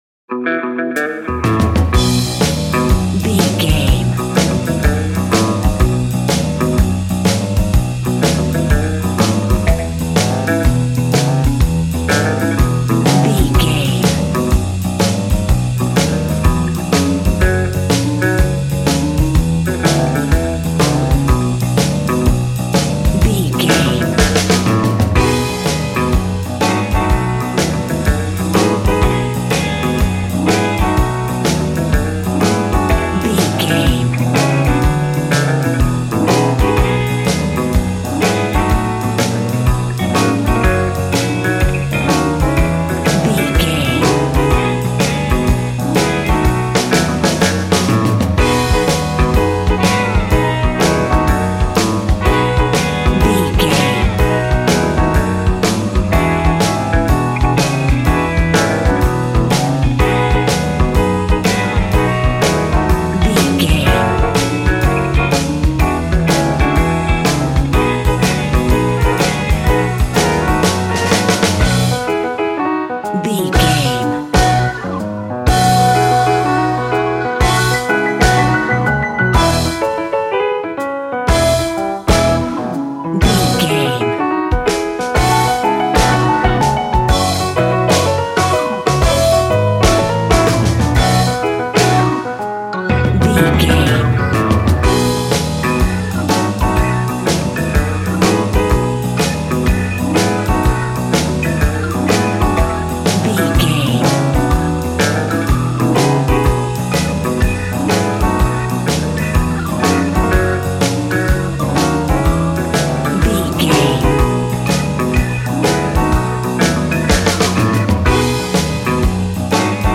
Ionian/Major
cheerful/happy
double bass
drums
piano
50s